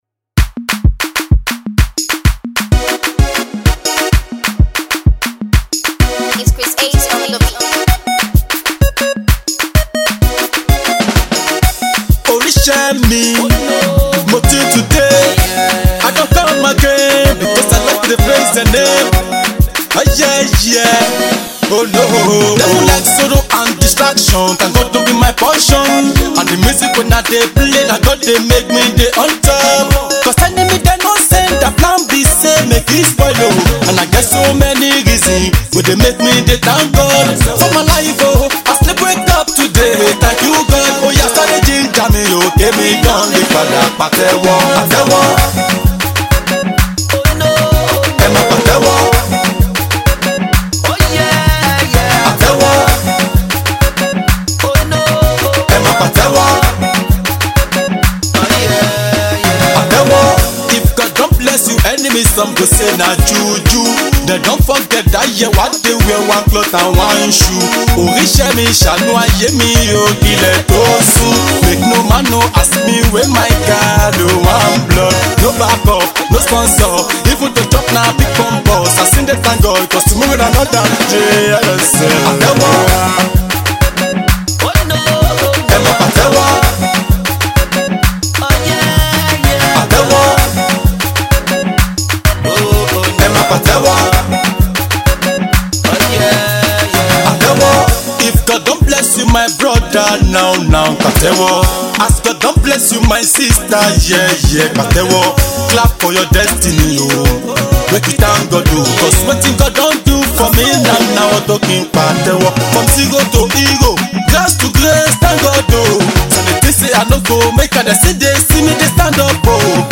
afro hip hop